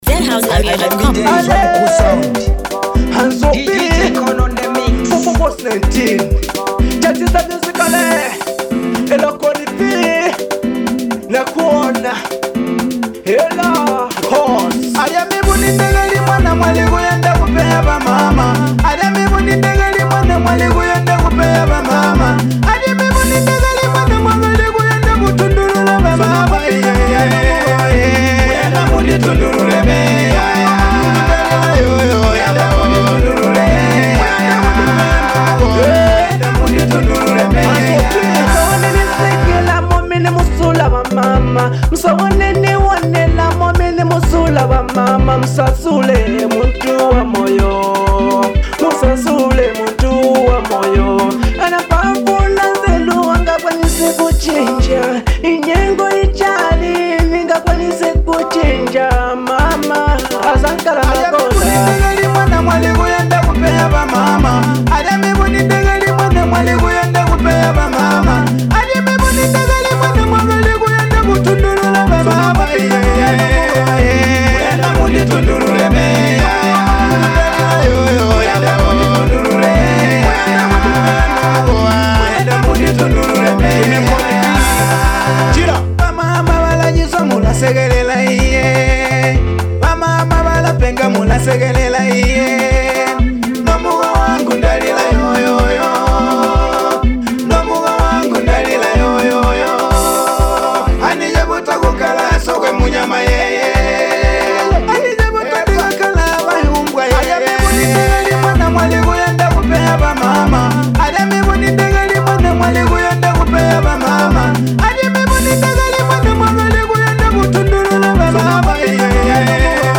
catchy banger